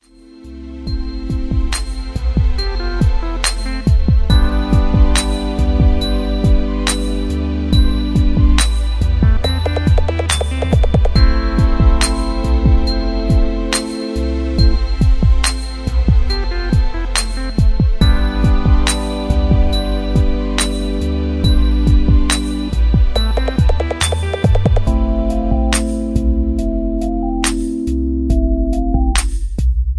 Smooth RNB Beat